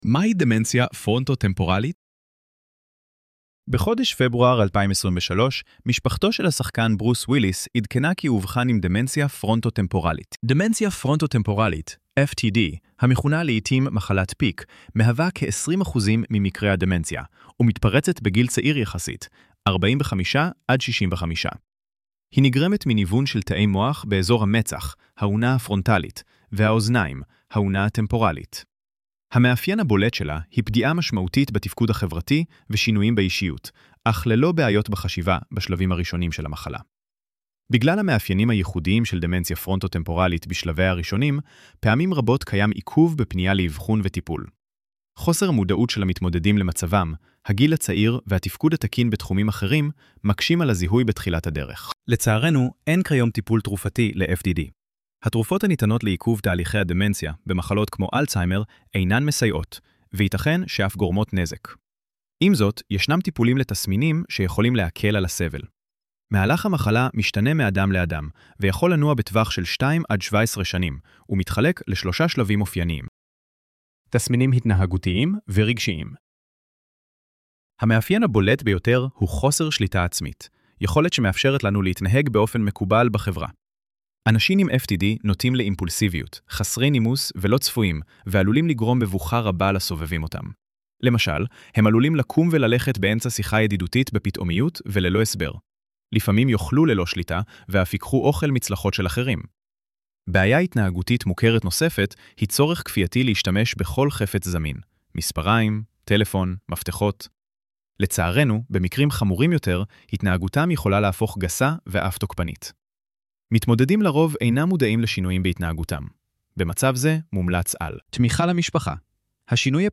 ElevenLabs_מהי_דמנציה_פרונטו-טמפורלית_.mp3